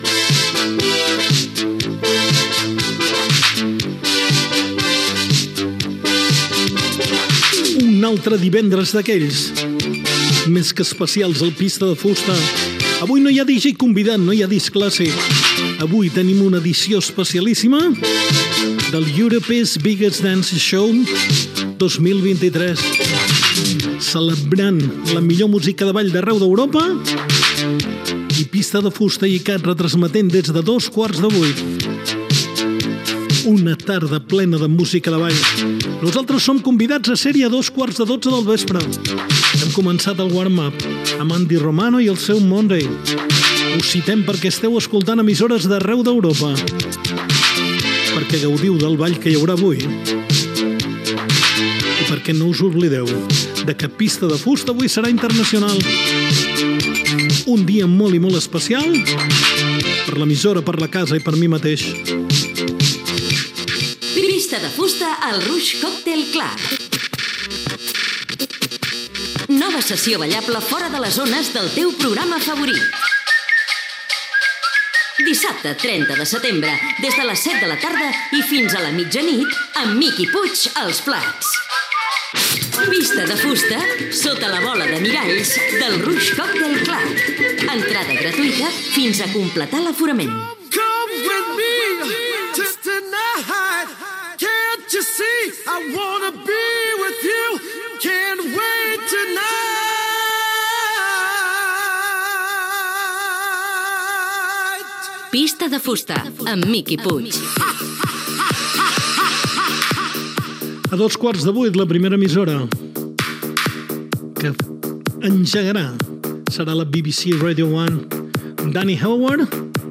Música, anunci que el programa participa a l'Europe’s Biggest Dance Show, indicatiu del programa, anunci del Rouge Cocktail Club, indicatiu, la BBC obrirà l'Europe’s Biggest Dance Show
Musical